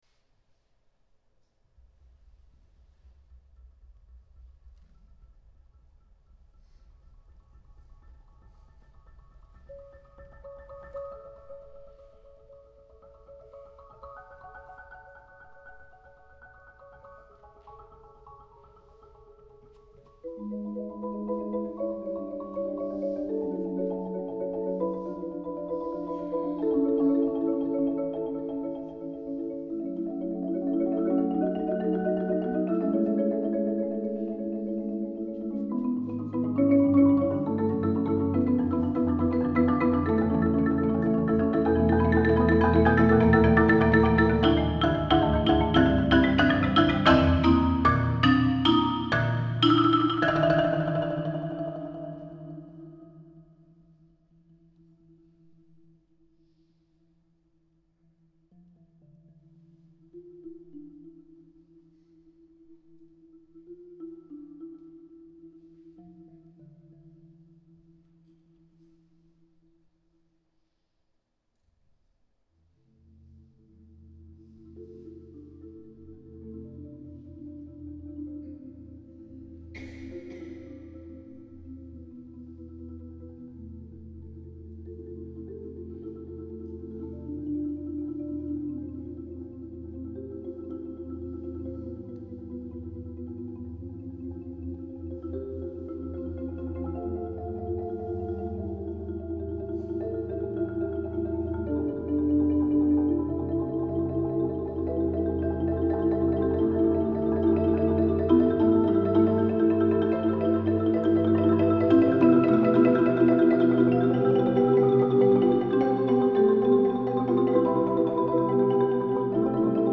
Jongsma Percussion Duo — voorjaarseditie 2022.